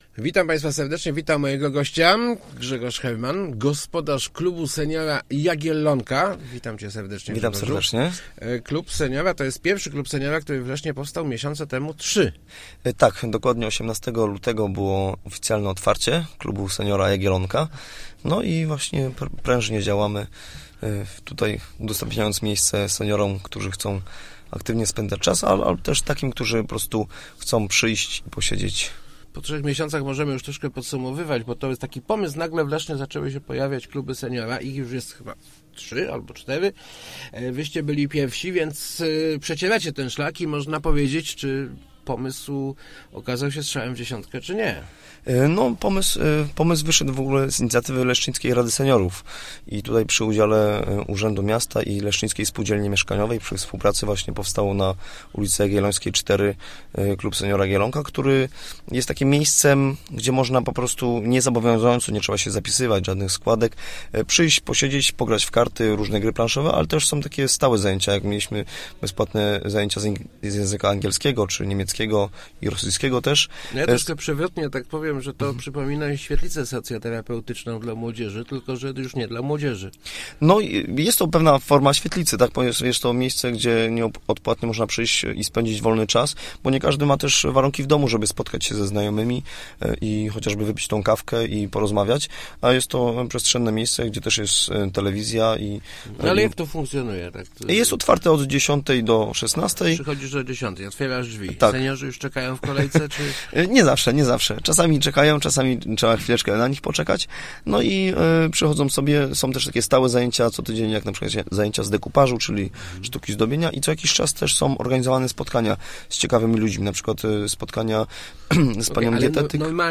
Start arrow Rozmowy Elki arrow Jagiellonka dla leszczyńskich seniorów